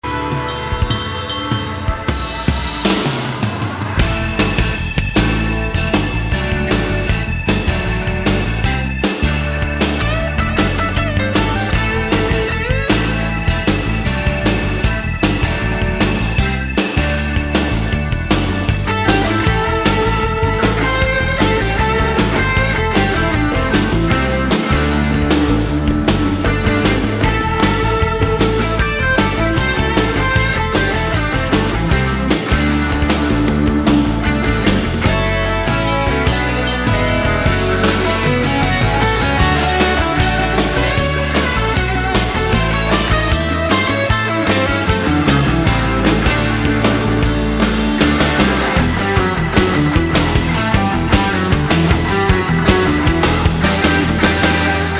mp3):  ??? on base guitar